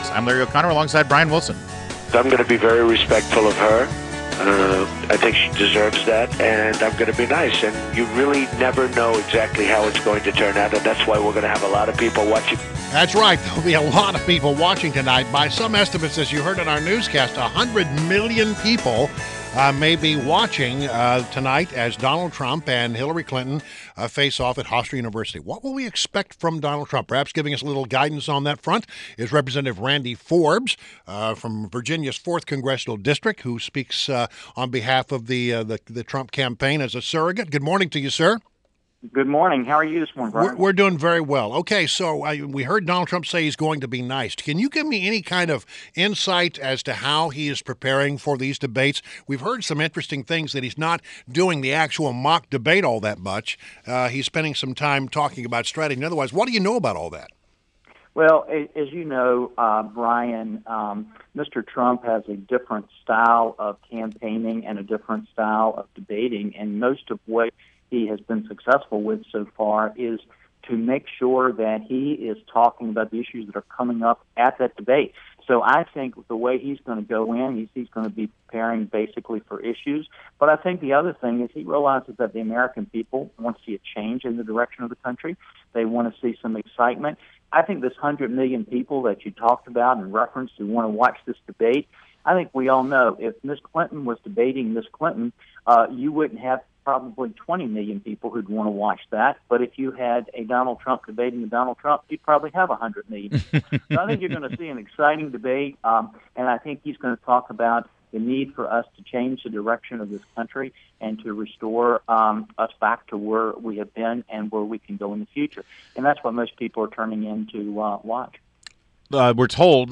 WMAL Interview - REP. RANDY FORBES - 09.26.16